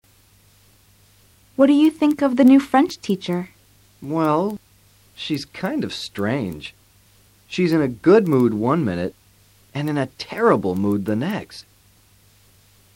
A continuación escucharás a cuatro parejas alabando o criticando a otras personas.